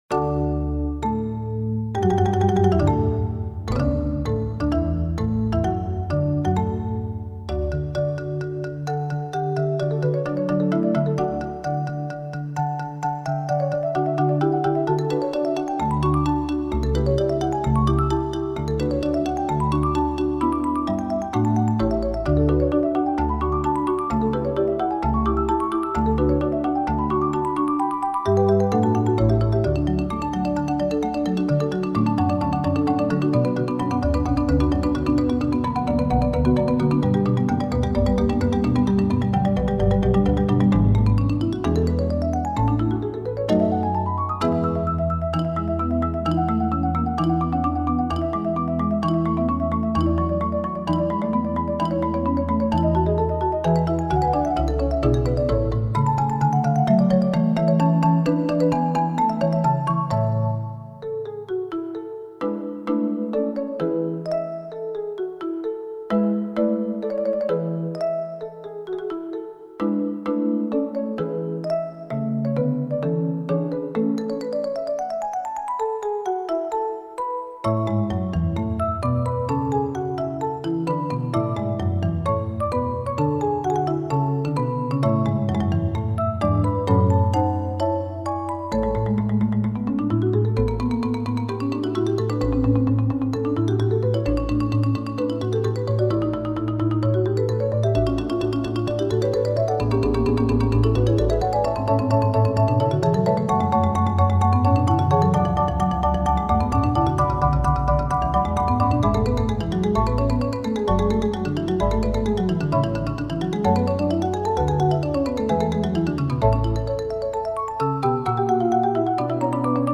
Соната ре мажор для двух фортепиано - Моцарт - слушать
Соната ре мажор для двух фортепиано - Вольфганг Амадей Моцарт. Классическая музыка величайшего композитора для взрослых и детей.
sonata-in-d-for-two-pianos.mp3